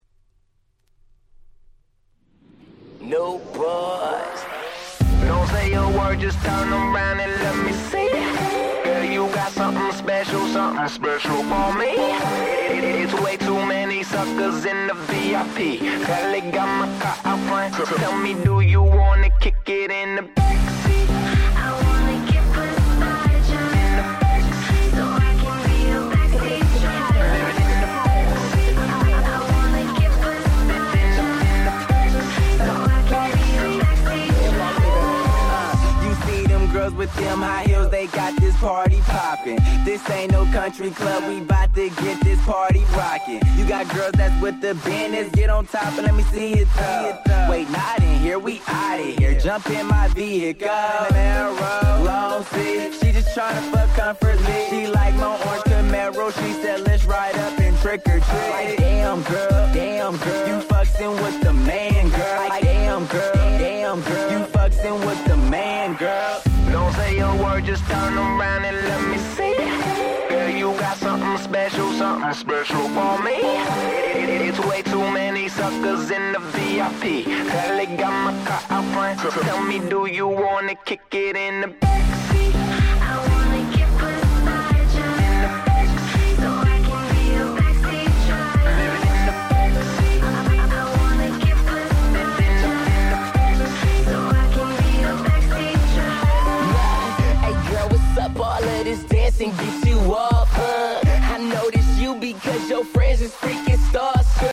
11' Big Hit Hip Hop !!